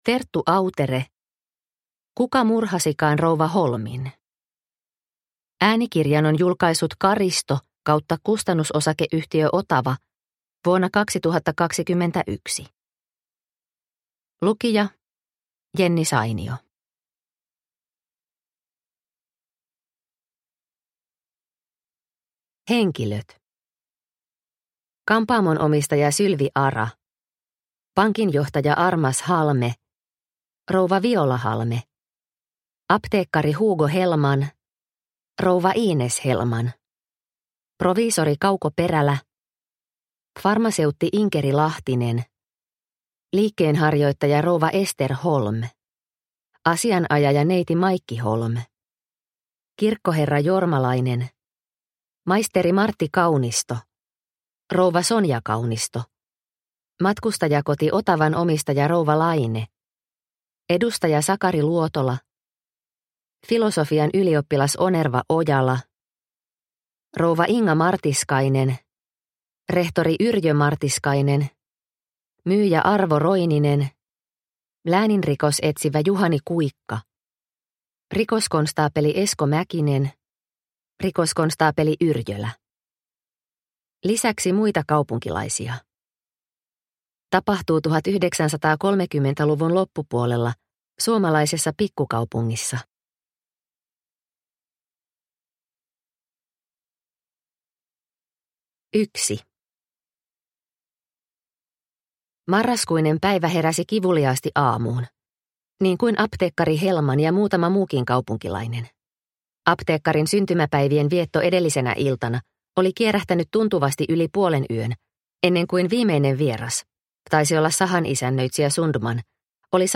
Kuka murhasikaan rouva Holmin? – Ljudbok – Laddas ner